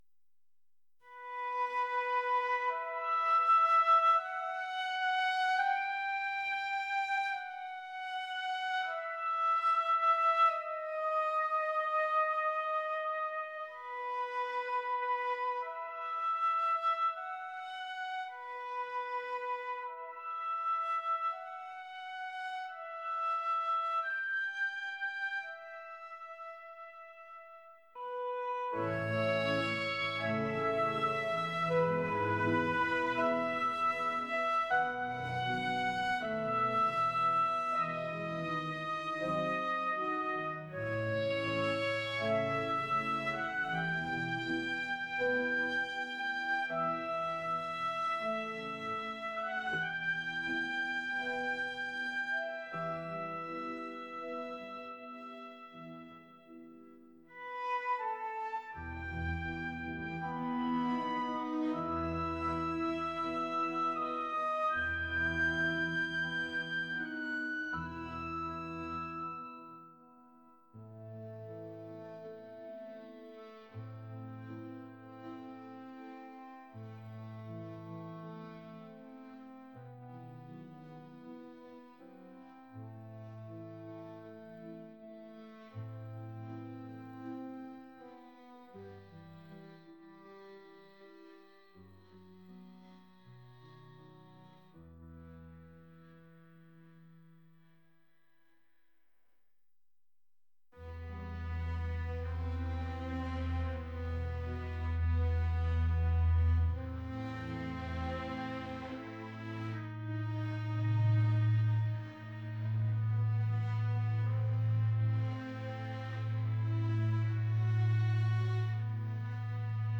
dreamy | classical